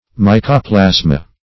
Meaning of mycoplasma. mycoplasma synonyms, pronunciation, spelling and more from Free Dictionary.